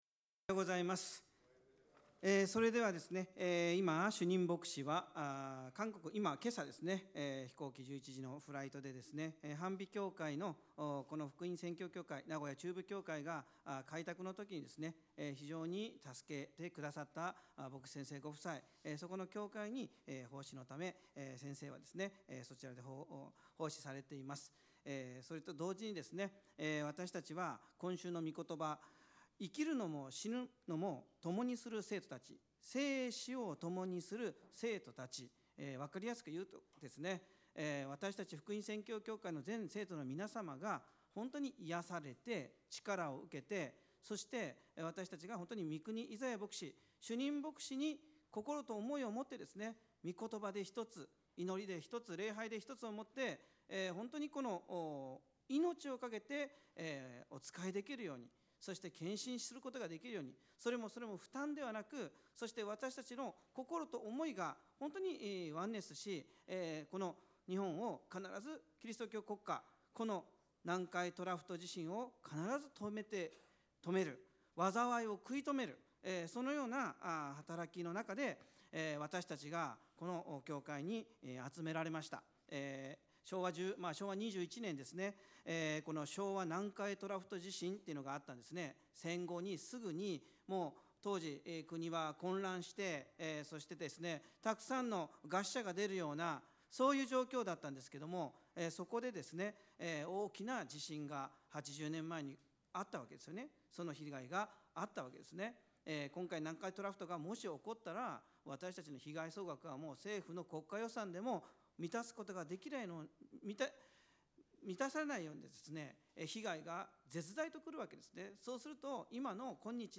主日2部メッセージ